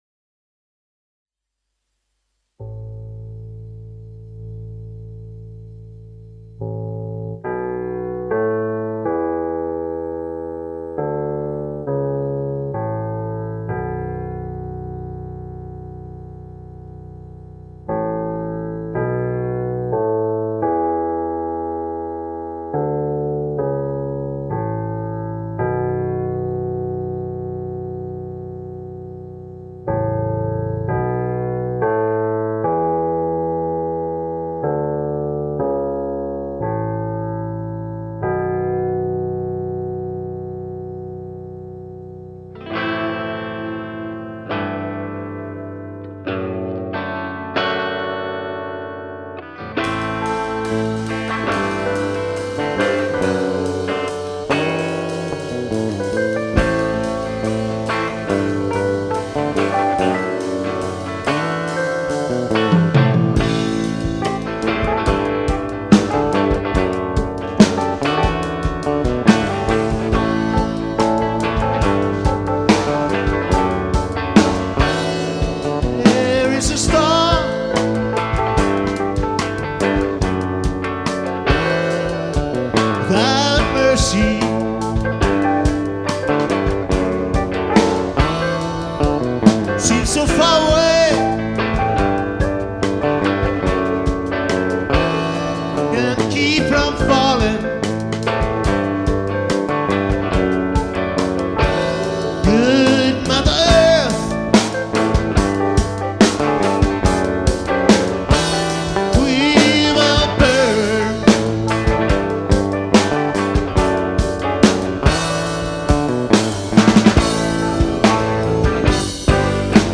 voc. e-guit.
keyb.
bass
drums